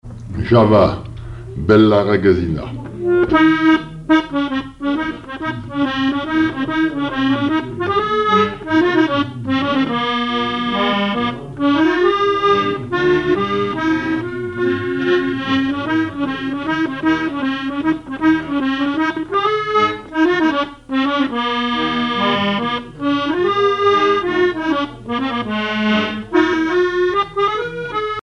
accordéon(s), accordéoniste
danse : java
Genre strophique
Pièce musicale inédite